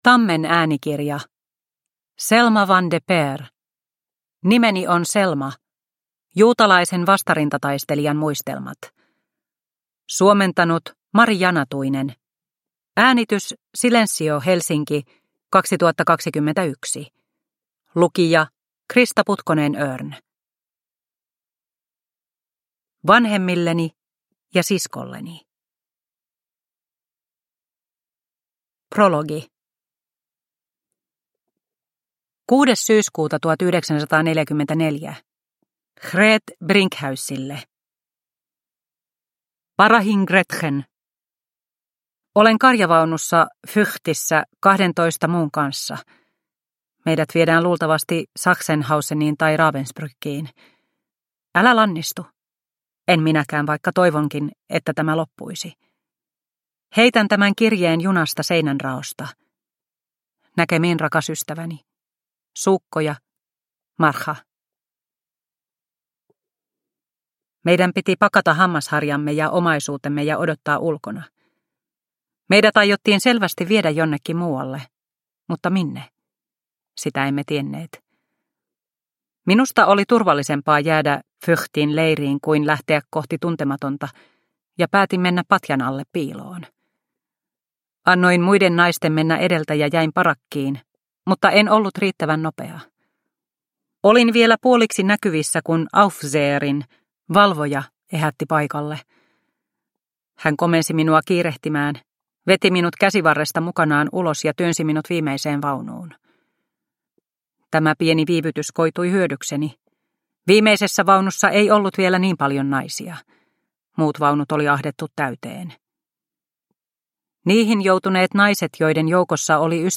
Nimeni on Selma – Ljudbok – Laddas ner